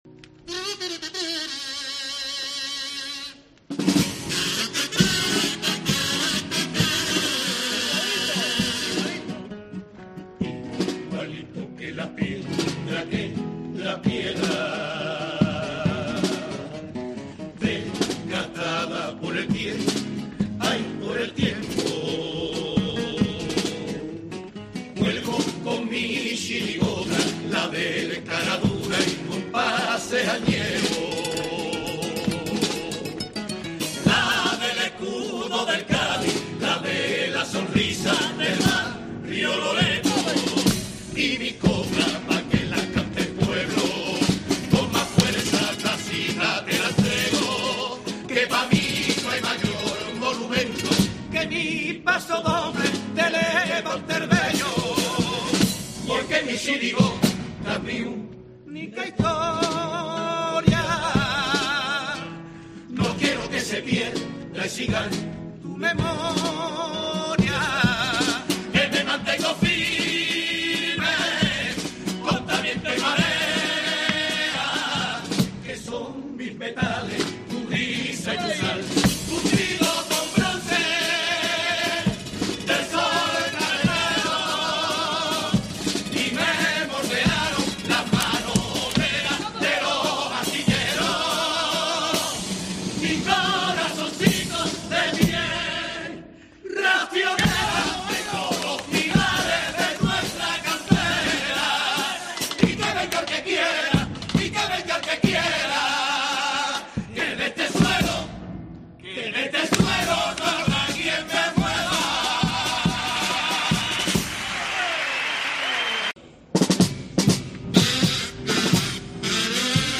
Escucha algunas de las letras más destacadas que se han escuchado en el Gran Teatro Falla en la modalidad de chirigotas
pasodoble